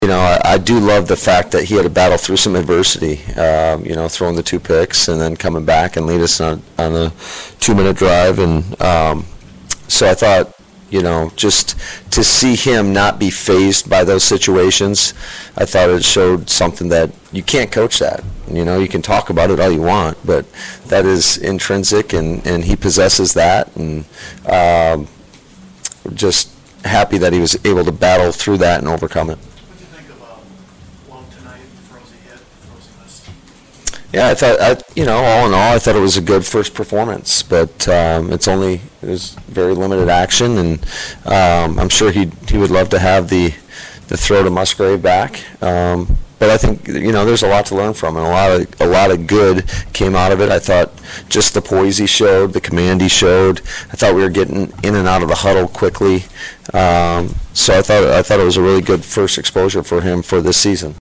Head Coach Matt LaFleur opened his post game visit by saluting the energy and enthusiasm the team played with from start to finish, there was so much to build off and teach from, but it didn’t take long to ask him about the QB’s, beginning with the Cincinnati kid, Clifford, who played his high school ball here and did some ballin’ back in his hometown.